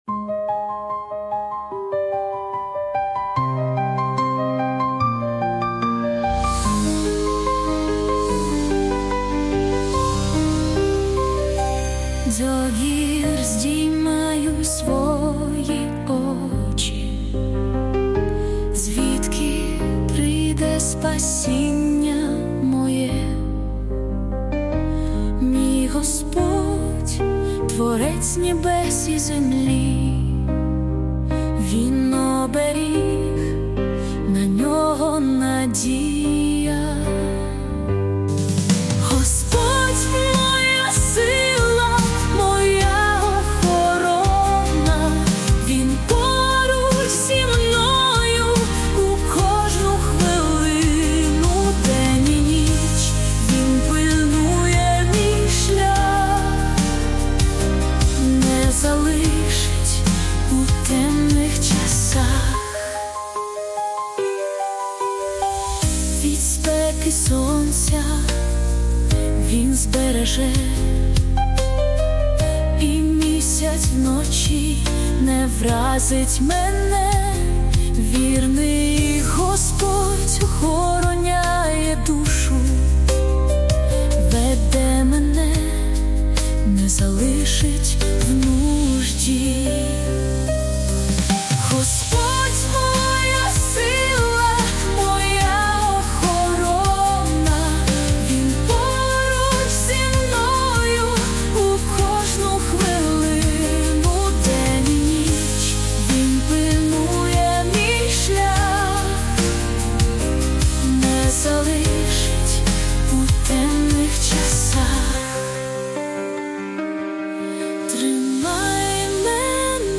песня ai
480 просмотров 955 прослушиваний 232 скачивания BPM: 73